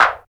Index of /90_sSampleCDs/Roland L-CD701/KIT_Drum Kits 3/KIT_Rap Kit 1